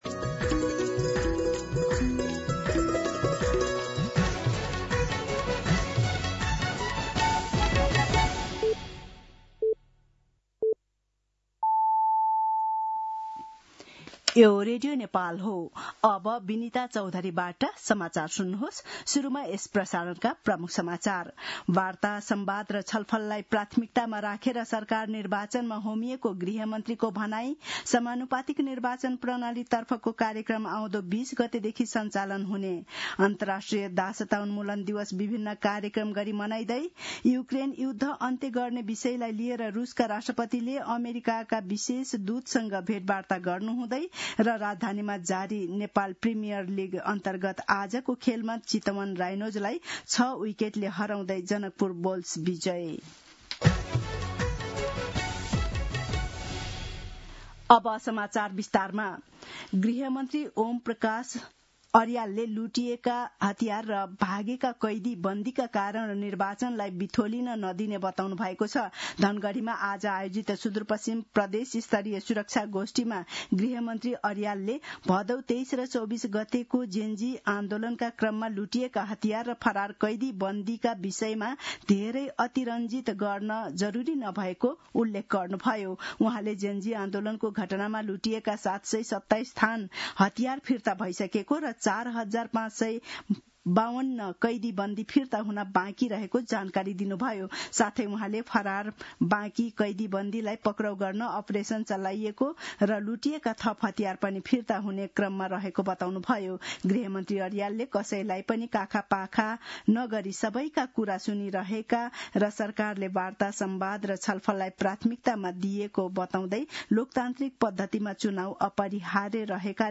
दिउँसो ३ बजेको नेपाली समाचार : १६ मंसिर , २०८२